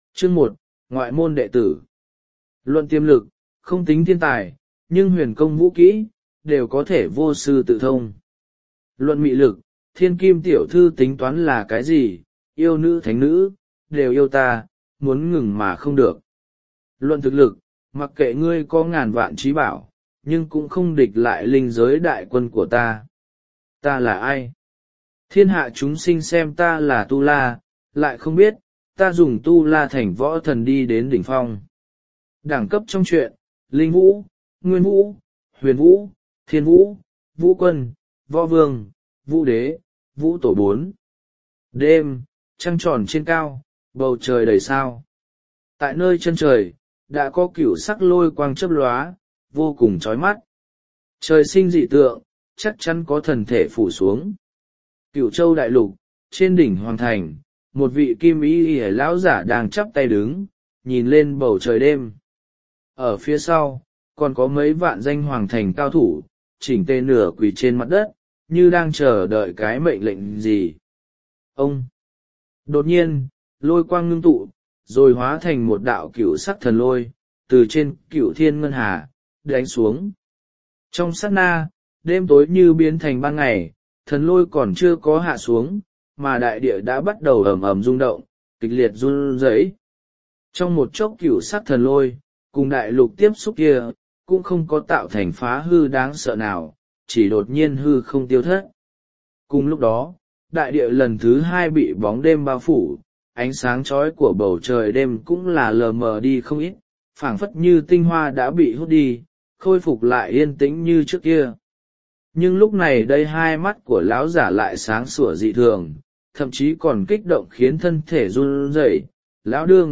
Tu La Vũ Thần Audio - Nghe đọc Truyện Audio Online Hay Trên AUDIO TRUYỆN FULL